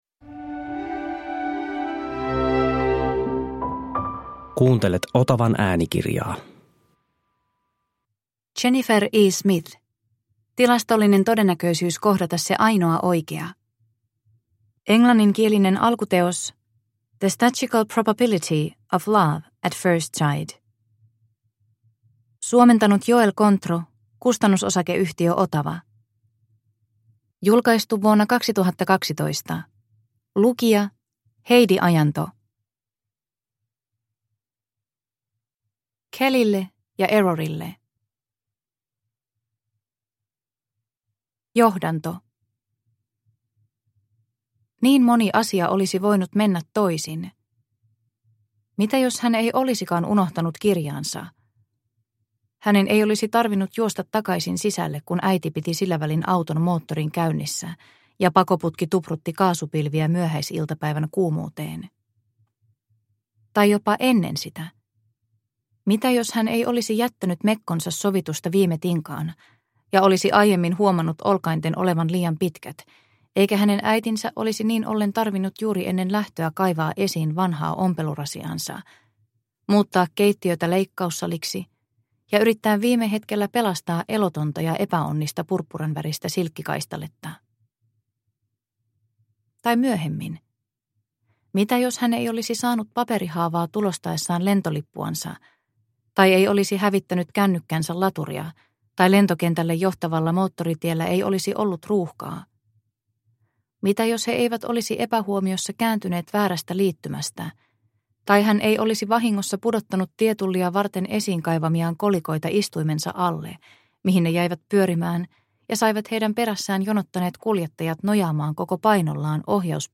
Tilastollinen todennäköisyys kohdata se ainoa oikea – Ljudbok – Laddas ner